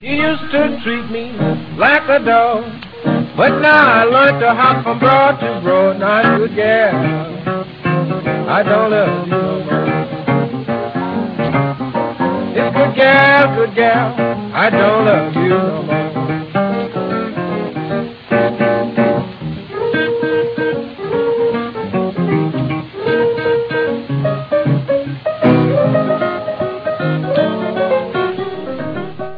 фортепиано, вокал
гитара